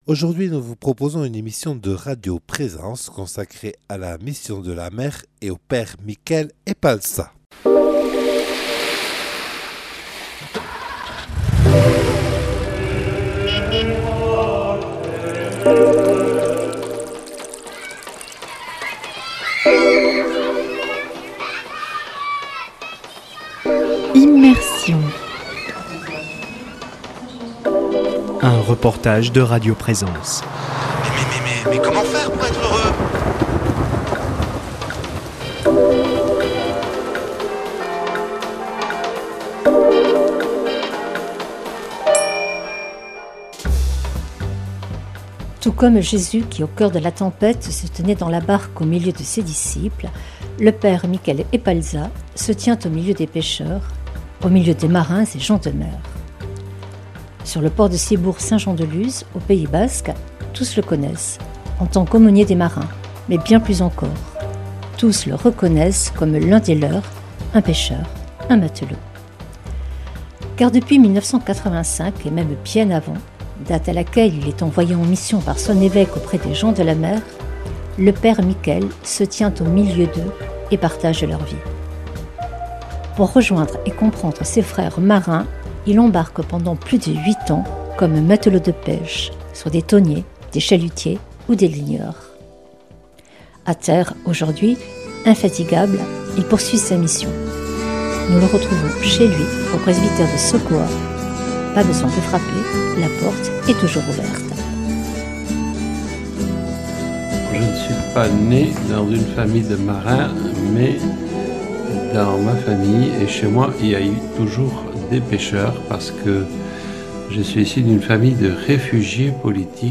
Un reportage réalisé par Radio Présence en 2019.